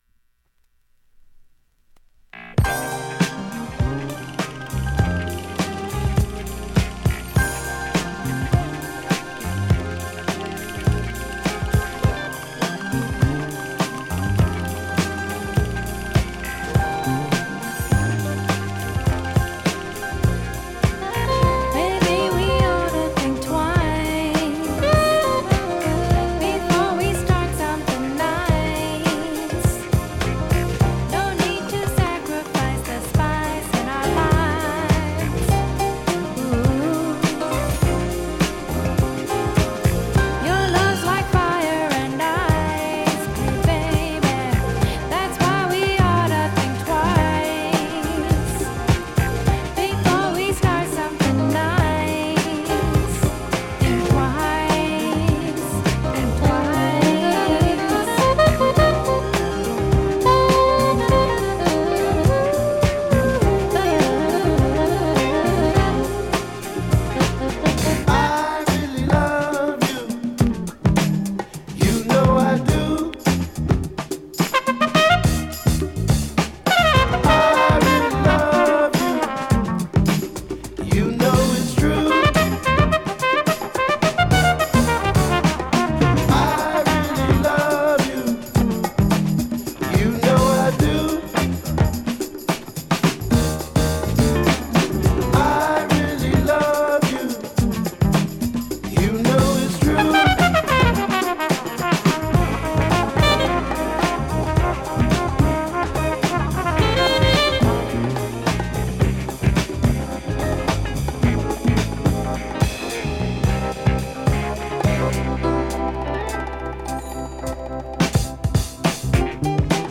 現物の試聴（両面すべて録音時間7分8秒）できます。